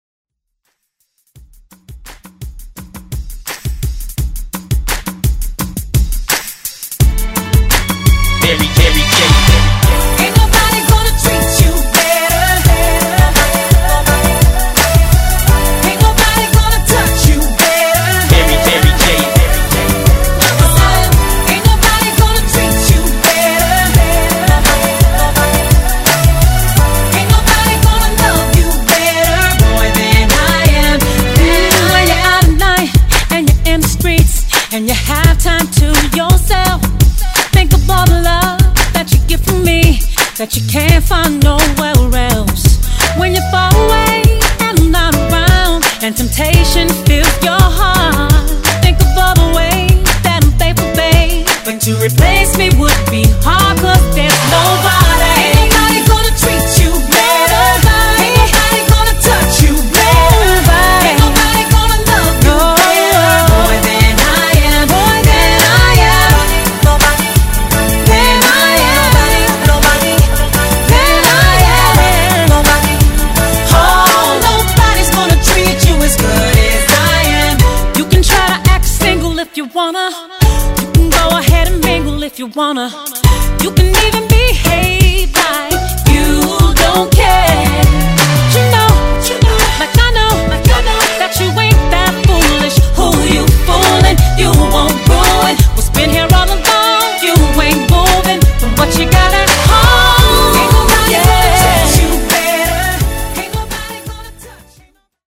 Genre: R & B
Clean BPM: 85 Time